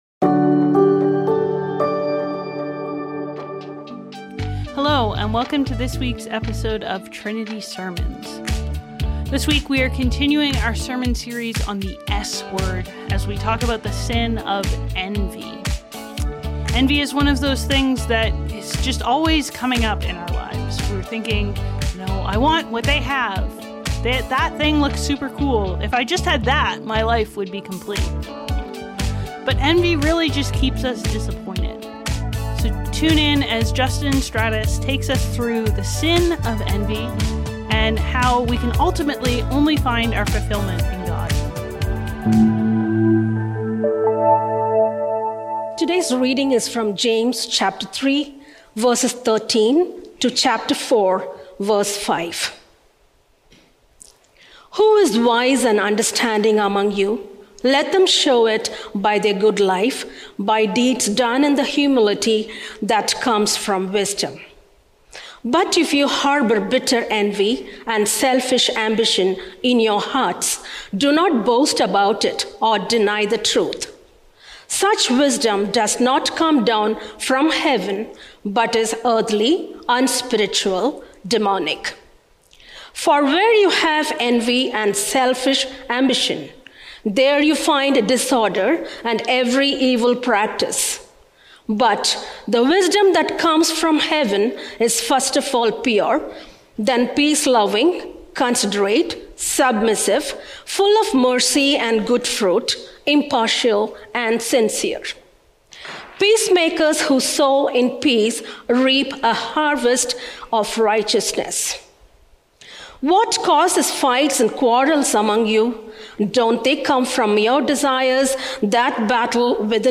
Trinity Streetsville - Ending Envy | The "S" Word | Trinity Sermons - Archive FM